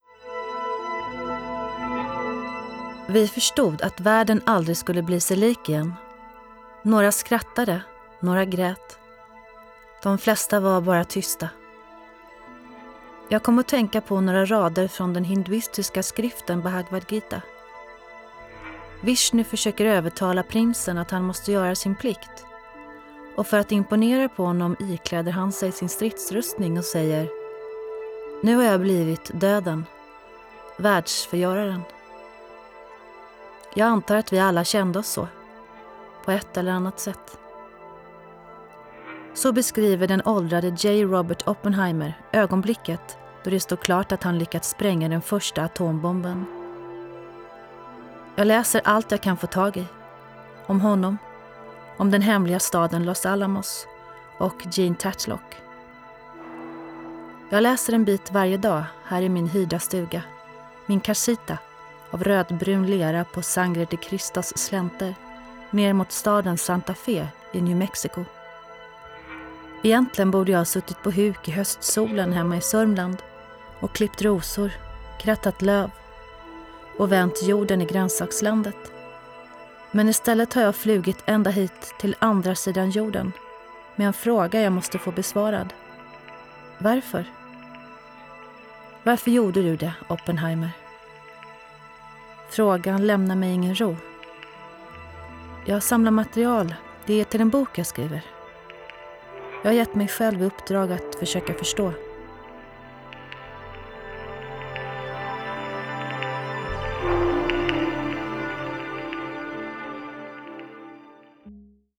RÖST Berättaren